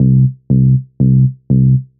低音循环
描述：来自FL 9的简单贝司使用得很好:)
Tag: 140 bpm Electro Loops Bass Loops 296.25 KB wav Key : Unknown